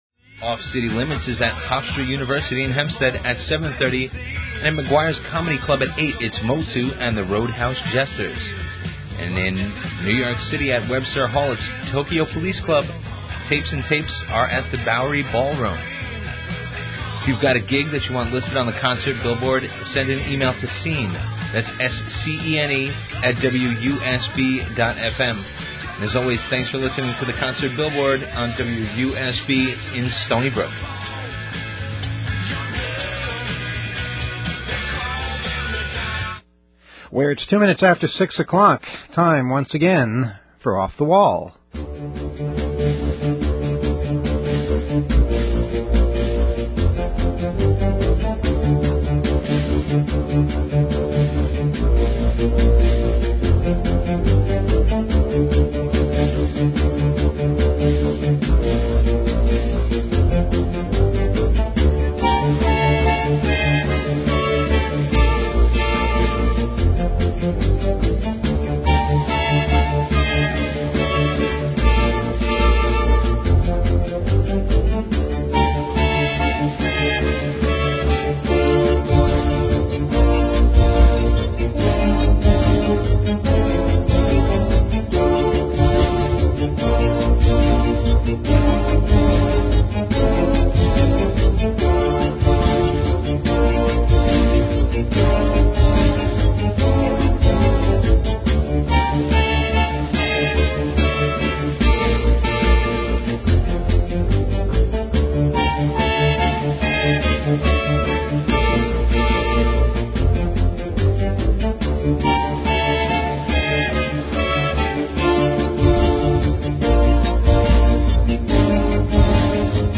the theme to the old "Voice of Long Island" show, the old shows are now being made available online